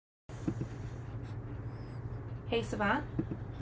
heysavant / wake-word